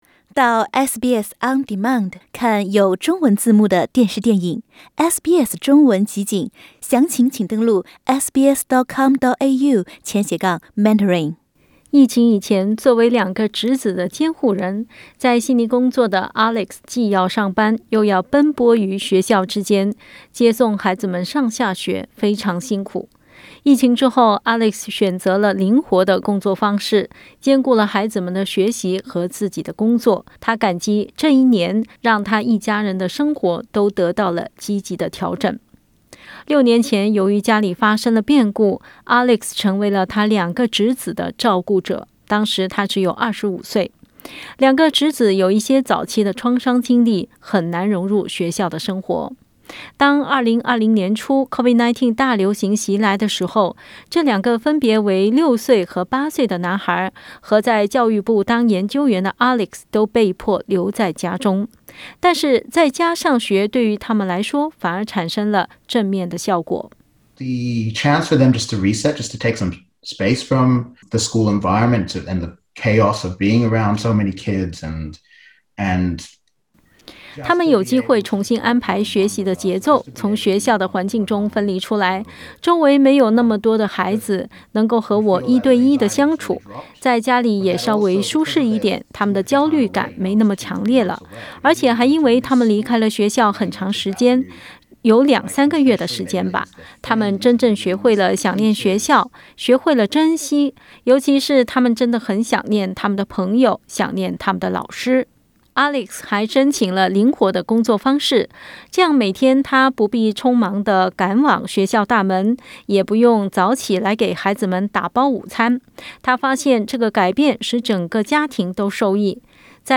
（请听报道） 澳大利亚人必须与他人保持至少 1.5 米的社交距离，请查看您所在州或领地的最新社交限制措施。